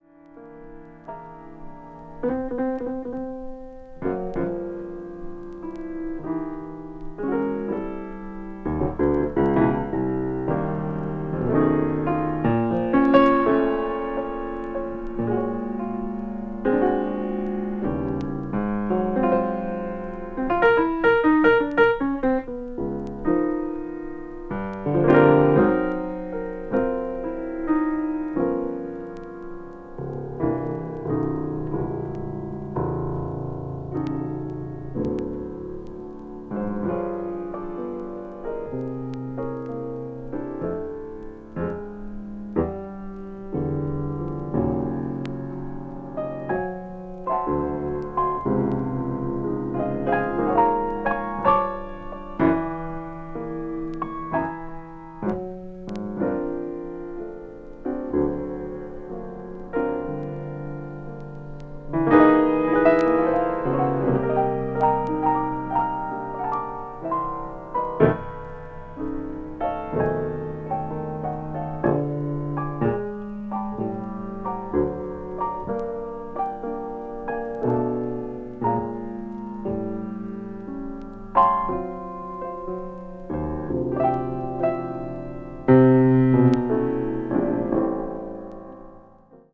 ベースとドラムはとても静かな演奏で
bop   cool jazz   modern jazz